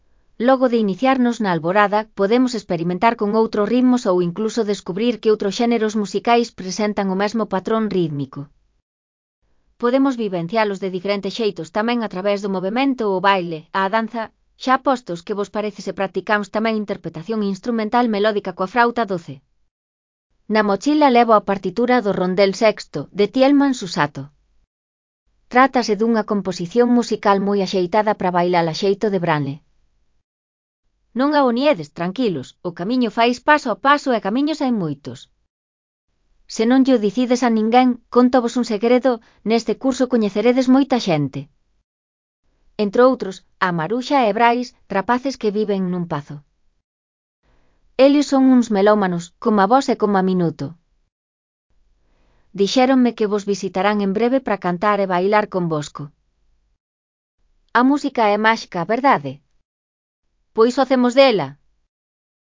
Trátase dunha composición musical moi axeitada para bailar a xeito de branle.